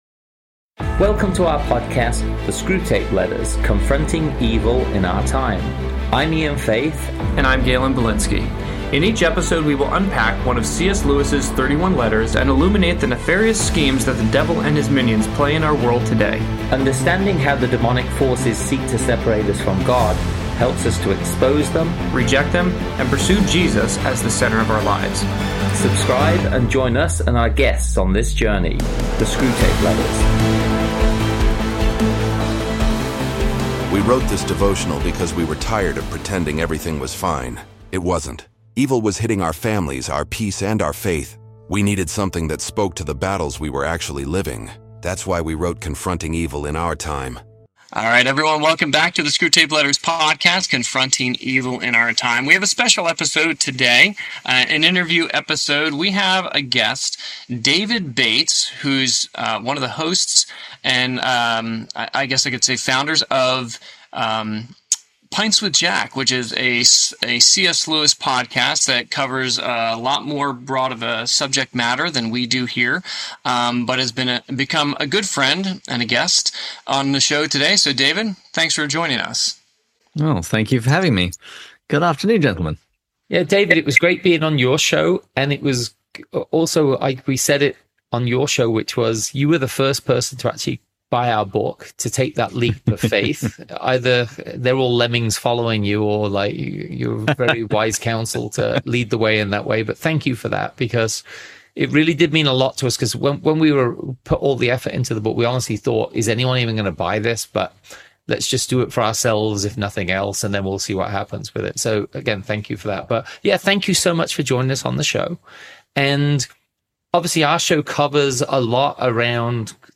This page contains some of the interviews I’ve given on other radio shows and podcasts.